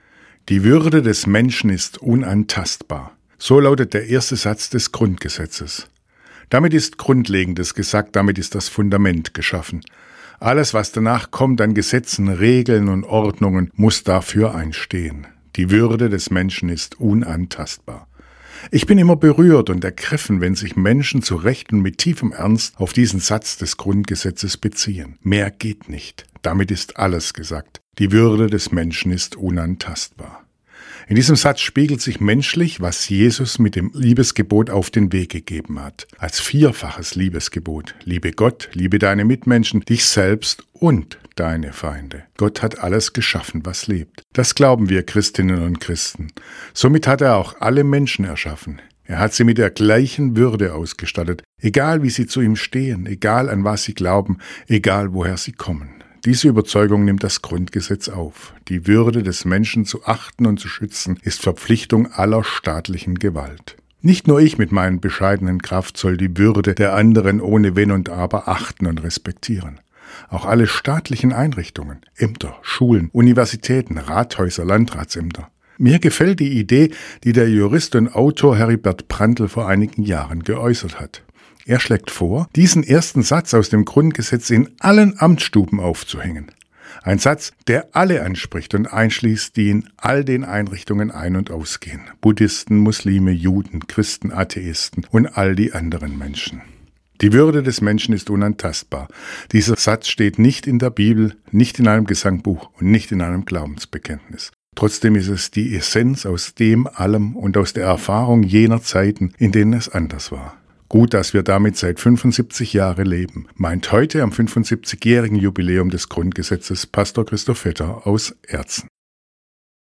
Radioandacht vom 23. Mai – radio aktiv